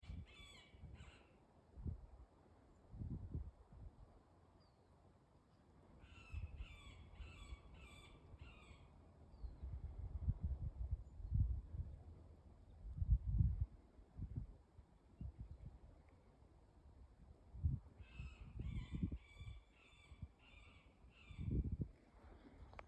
средний дятел, Leiopicus medius
СтатусПоёт